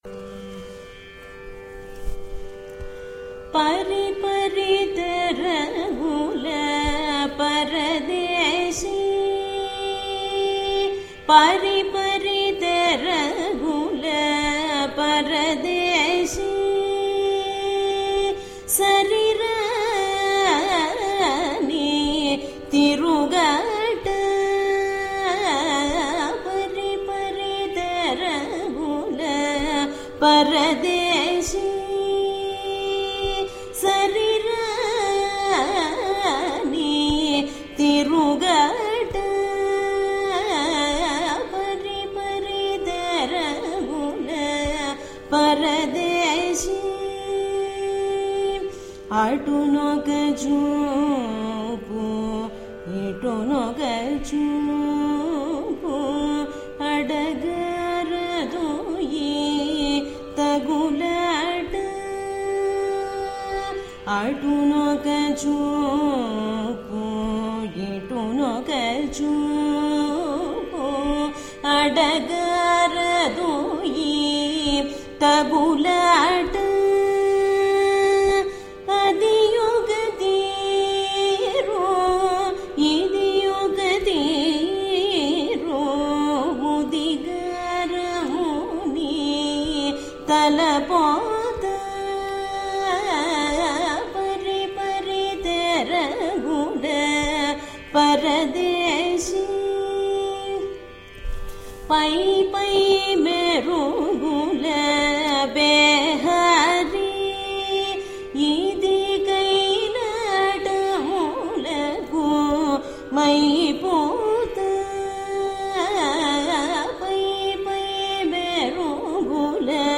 రాగం: షణ్ముఖప్రియ
తాళం: ఆది